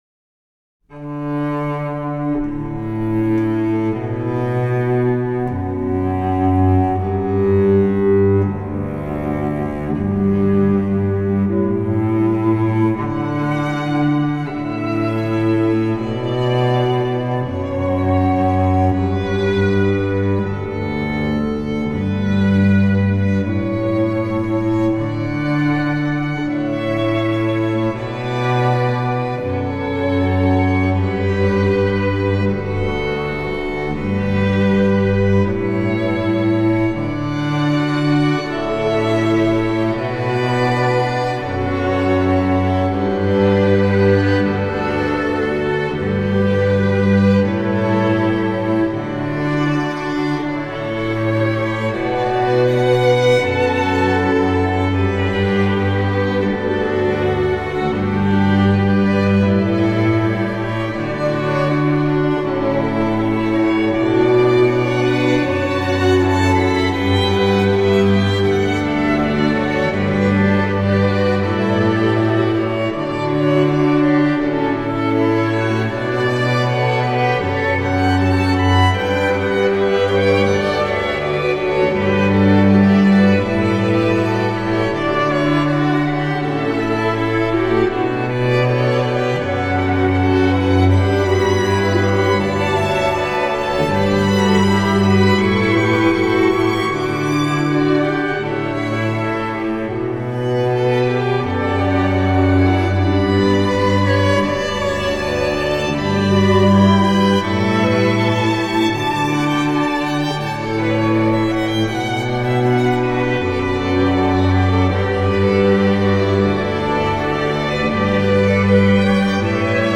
Hall Effect Samples
Lexicon PCM96
Preset - Large Hall
Hall_PCM96_Large_Hall.mp3